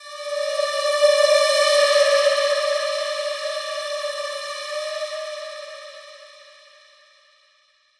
Session 14 - Spooky Pad 01.wav